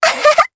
Dosarta-Vox-Laugh_jp.wav